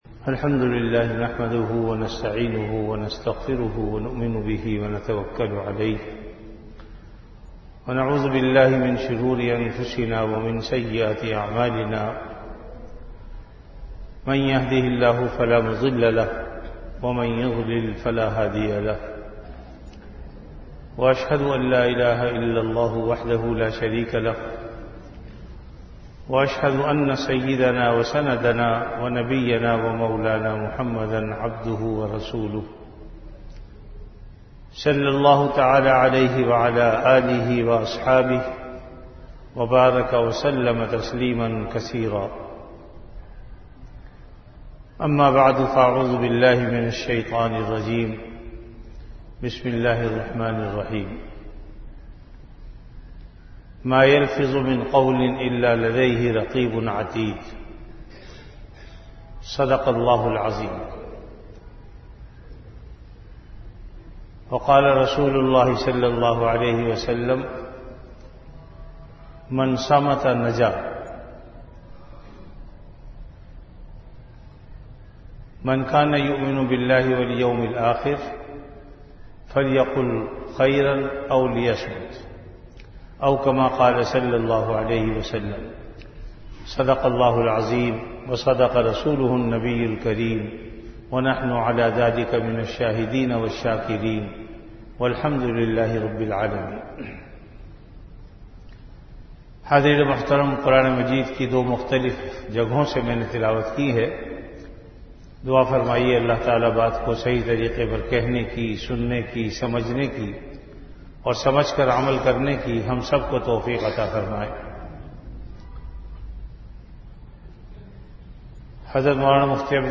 Bayanat
Before Juma Prayer
Jamia Masjid Bait-ul-Mukkaram, Karachi